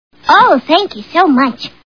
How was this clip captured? The Simpsons [Lisa] Cartoon TV Show Sound Bites